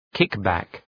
Προφορά
{‘kık,bæk}